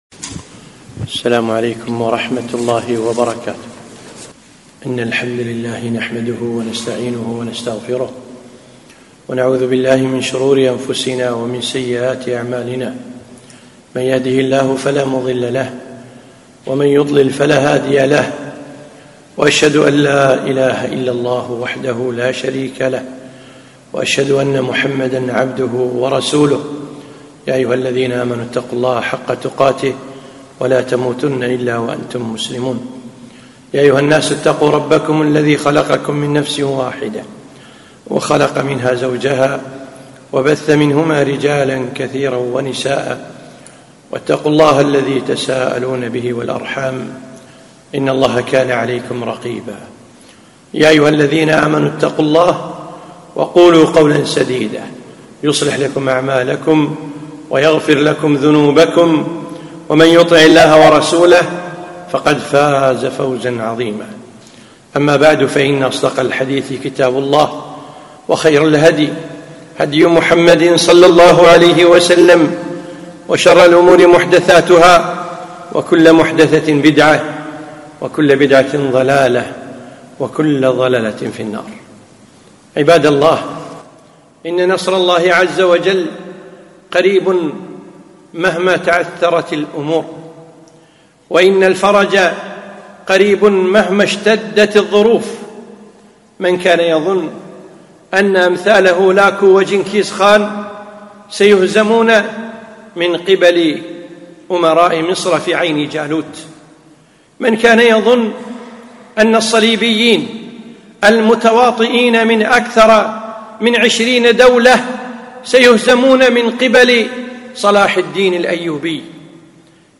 خطبة - نصر قريب